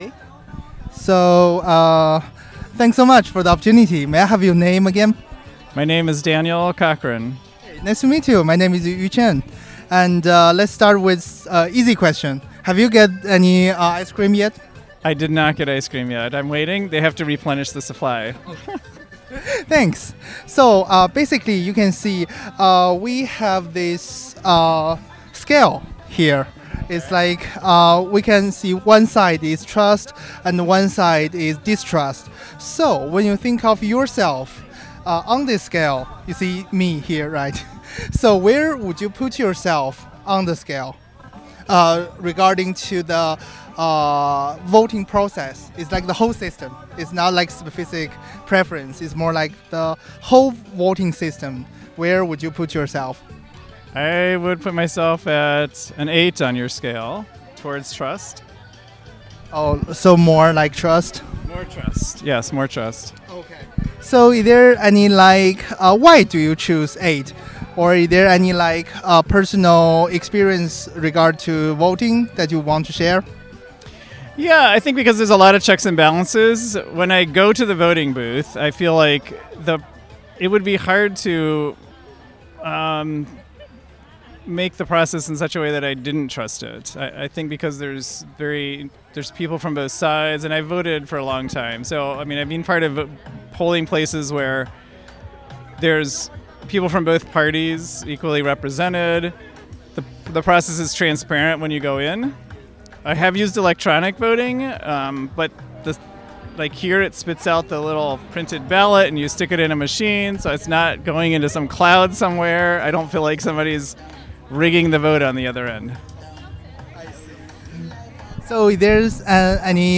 VIA Ice Cream Social